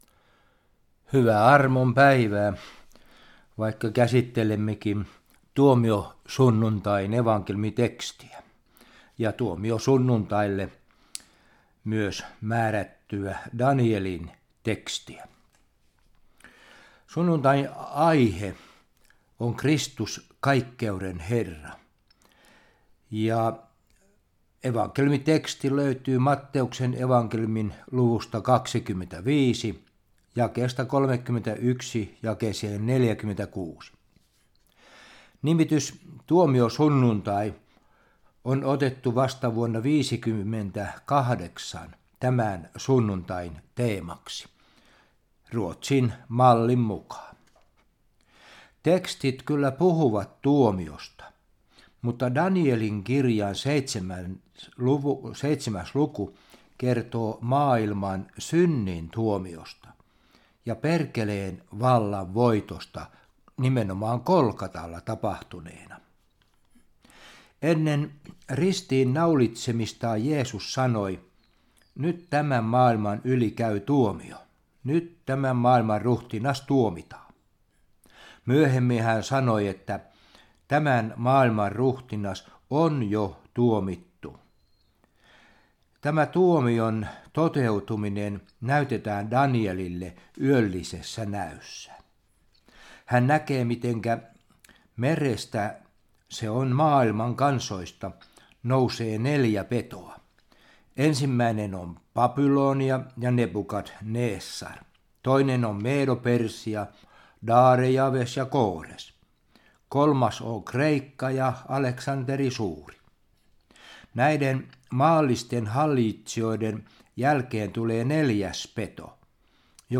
Evankeliumisaarna Järviradion